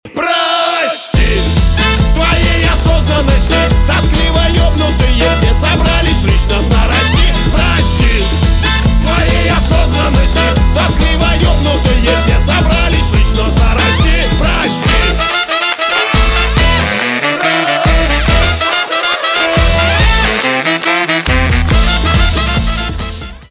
русский рок
труба , барабаны , хлопки